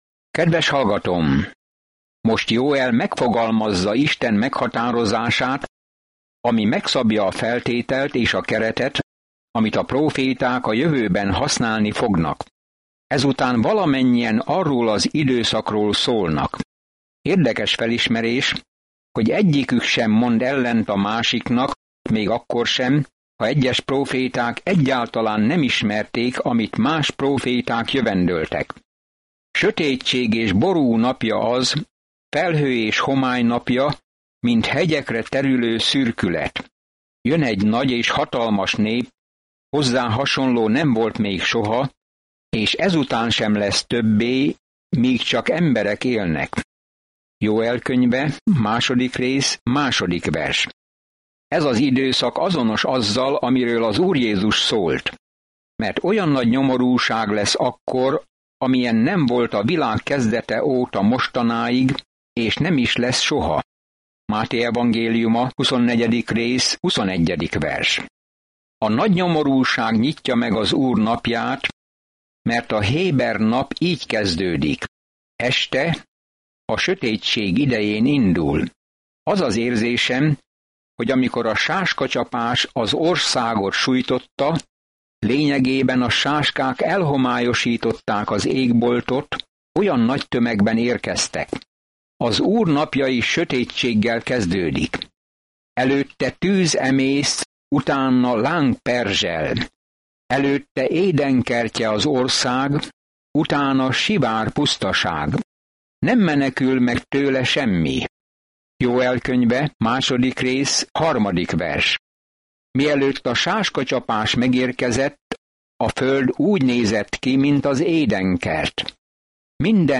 Szentírás Jóel 2:2-13 Nap 3 Olvasóterv elkezdése Nap 5 A tervről Isten sáskák csapását küldi, hogy ítéljék Izraelt, de ítélete mögött egy prófétai jövendőbeli „Úr napjának” leírása áll, amikor Isten végre kimondja a szavát. Napi utazás Joelen keresztül, miközben hallgatod a hangos tanulmányt, és olvasol válogatott verseket Isten szavából.